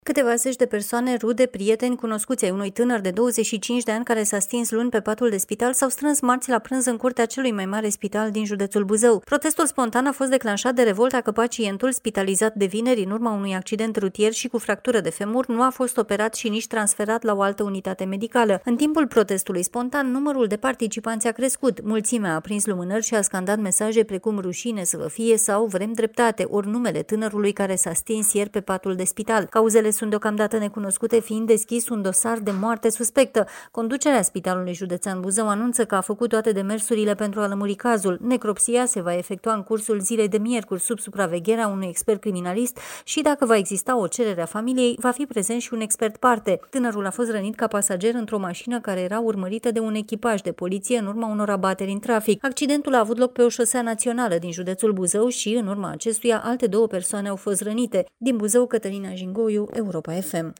Un protest spontan a avut loc marți în curtea Spitalului Județean din Buzău, după ce un tânăr de 25 de ani, rănit într-un accident rutier, s-a stins, după câteva zile de la internare. Prietenii și rudele acuză faptul că personalul medical nu ar fi intervenit la timp.
Mulțimea a aprins lumânări și a scandat mesaje precum „RUȘINE SĂ VĂ FIE” sau „VREM DREPTATE” ori numele tânărului care s-a stins ieri pe patul de spital.